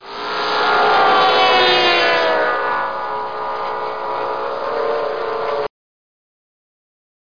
00315_Sound_diveplane3
1 channel